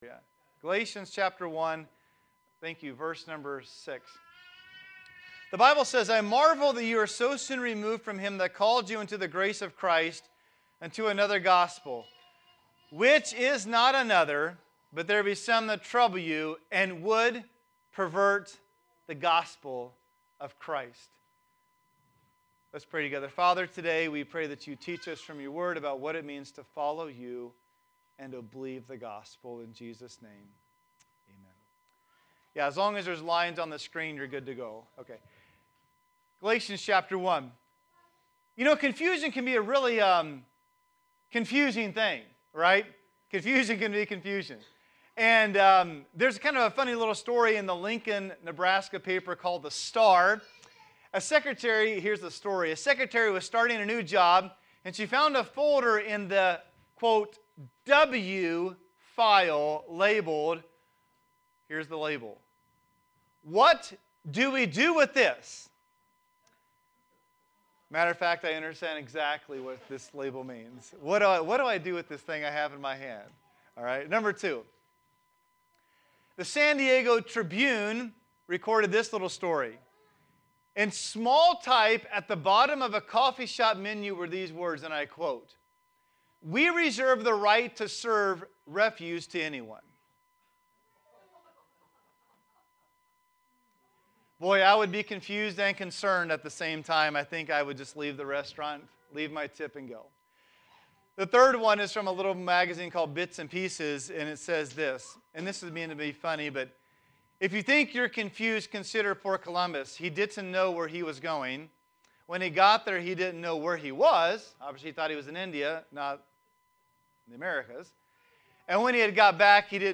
Sermons - Immanuel Baptist Church